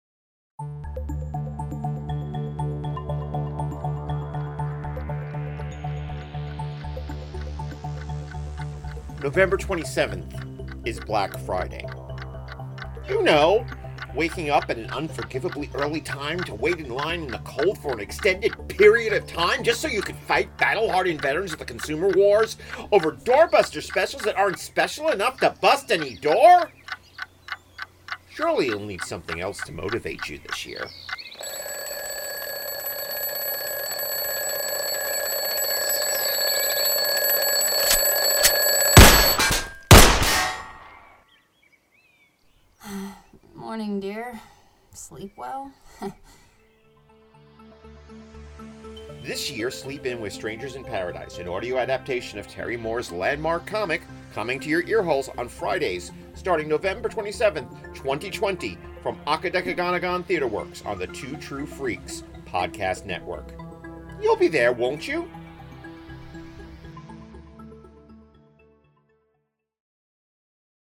Strangers In Paradise – The Audio Drama!
strangers-in-paradise-the-audio-drama.mp3